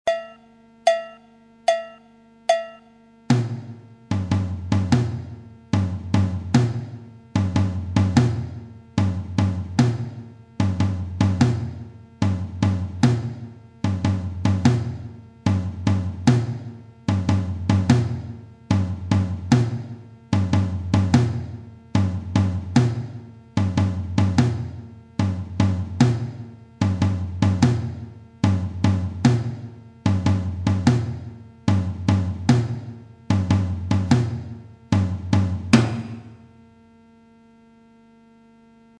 Quand il est joué seul il est frappé sur le premier temps fermé (étouffé), et sur le deuxieme temps, ouvert en résonnance et faisant des syncopes.
Le surdo Syncopé.
Figure de base du surdo syncopé et du contre surdo aigue dans la bossa Téléchargez ou écoutez dans le player.